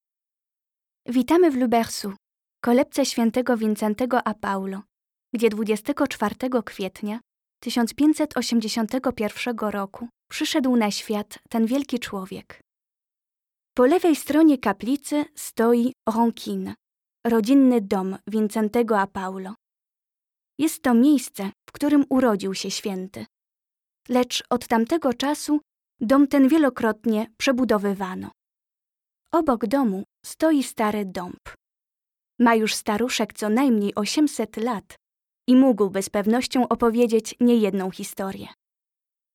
Comédienne voix-off franco-polonaise
Sprechprobe: Sonstiges (Muttersprache):